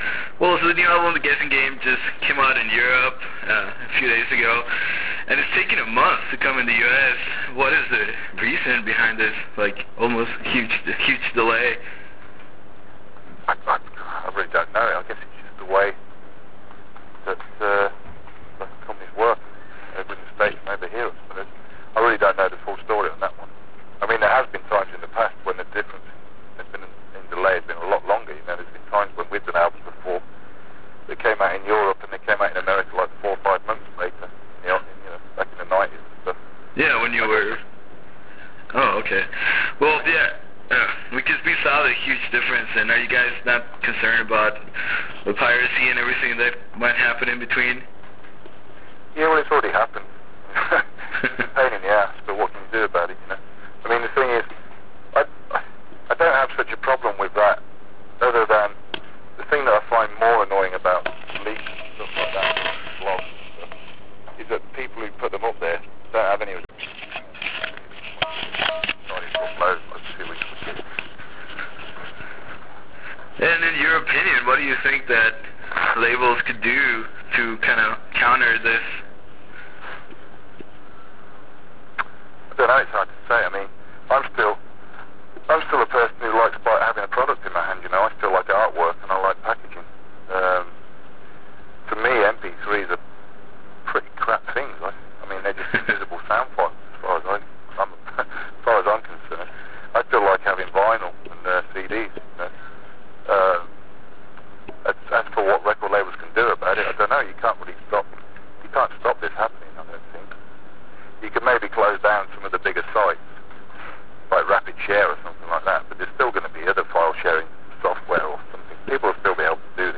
With the upcoming release of “The Guessing Game”, we had a conversation with Cathedral’s vocalist Lee Dorian. In this phone interview with talked about the new album and how it was conceived. We also discussed the twisted ideas behind the release among many other things.